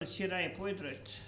Localisation Soullans
Catégorie Locution